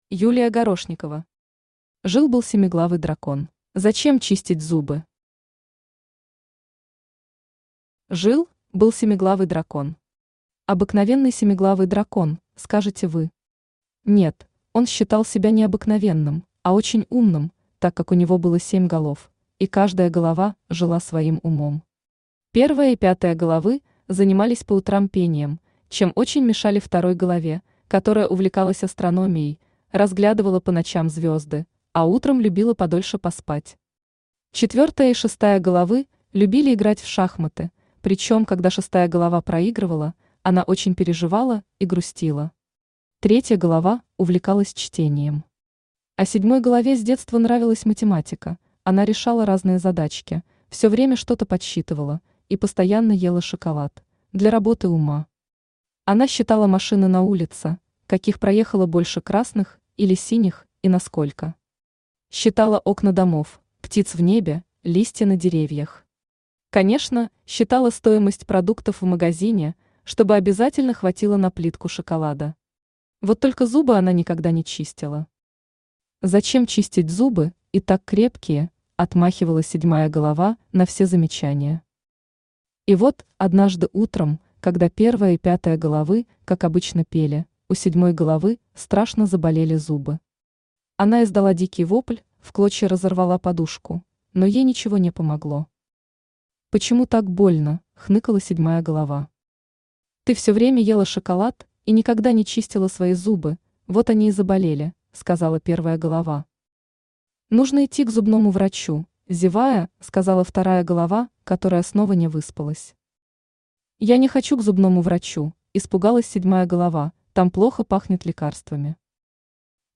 Аудиокнига Жил-был семиглавый дракон…
Aудиокнига Жил-был семиглавый дракон… Автор Юлия Владимировна Горошникова Читает аудиокнигу Авточтец ЛитРес.